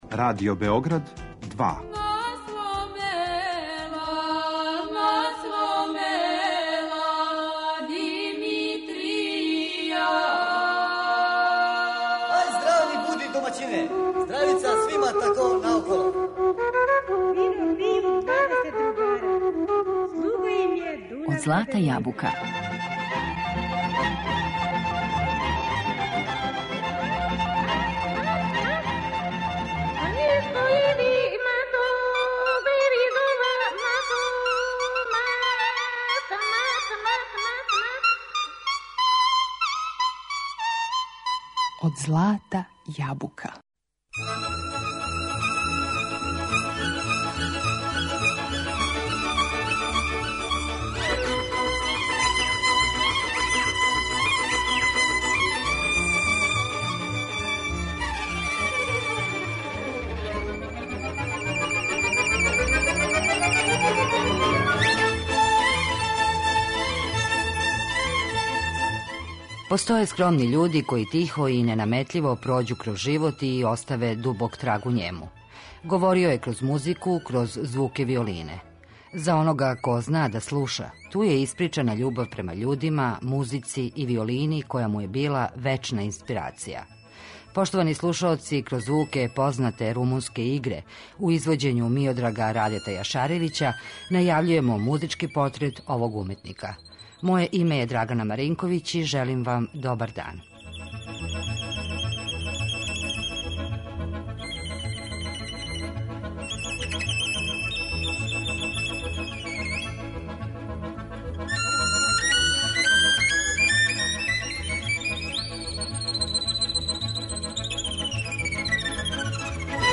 Говорио је кроз музику, кроз звук виолине.